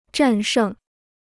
战胜 (zhàn shèng) พจนานุกรมจีนฟรี